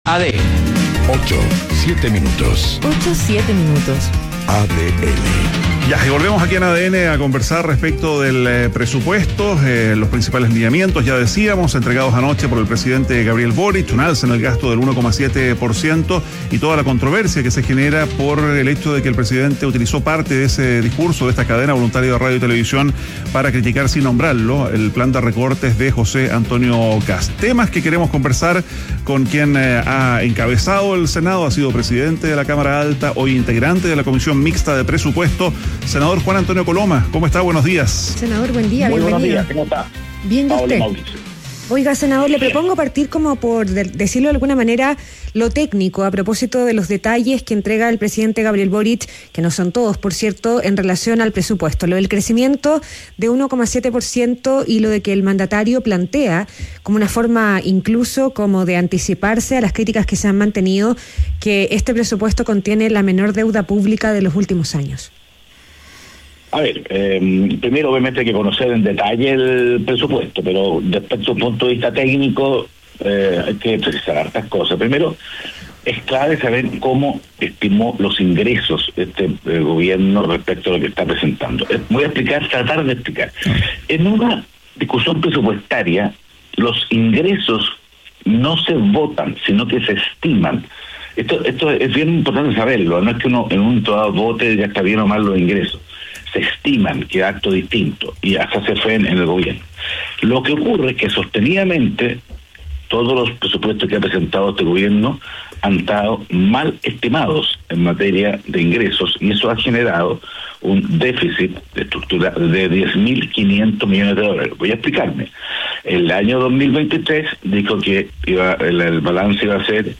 Entrevista a senador UDI Juan Antonio Coloma - ADN Hoy